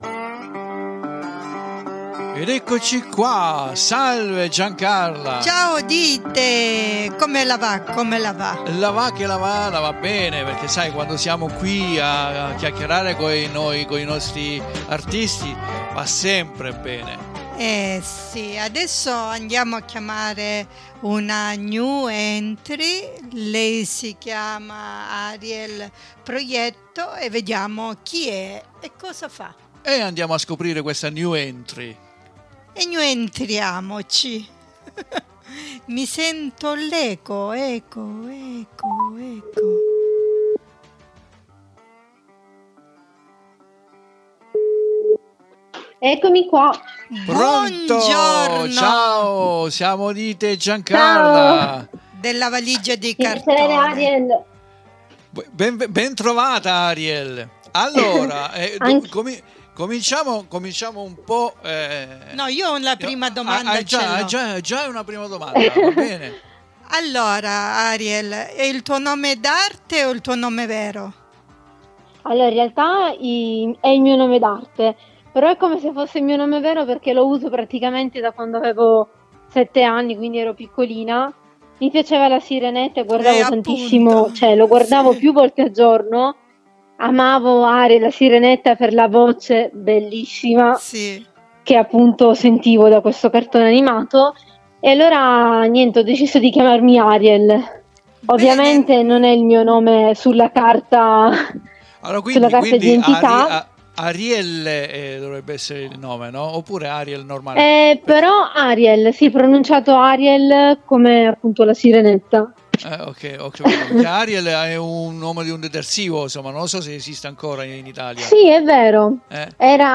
VI LASCIO ALL'INTERVISTA CONDIVISA QUI IN DESCRIZIONE!